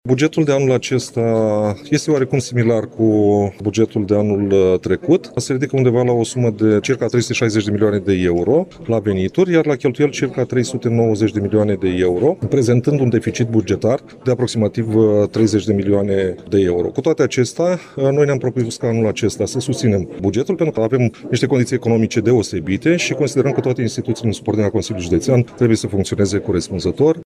Cristian Stanciu a justificat votul pentru buget prin faptul că instituțiile din subordinea Consiliului Județean trebuie să funcționeze: